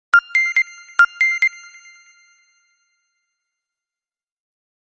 Dzwonek - Powiadomienie
Cykliczny dźwięk typowego powiadomienia.
powiadomienie.mp3